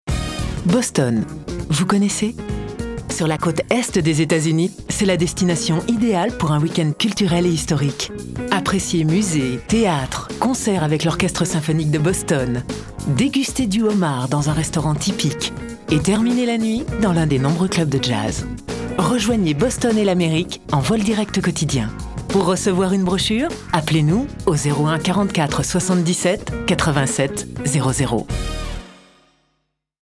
VOIX OFF
INSTITUTIONNEL